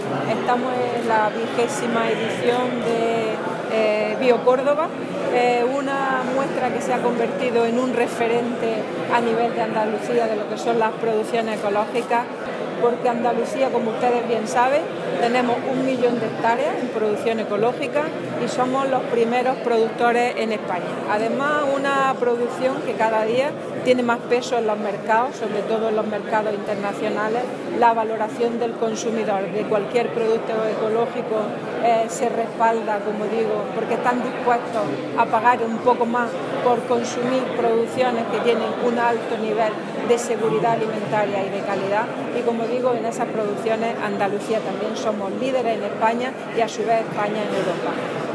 Declaraciones Carmen Ortiz sobre BioCórdoba